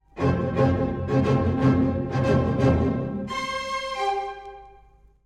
サラバンドのリズムが何度も奏でられた末、
CからGの強烈な下降音型が、斬首の描写であると言われています。
（この音源はめっちゃあっさりしてるな…(;^_^）
斬首後のコラールは、まるで死後の天からの声のようです！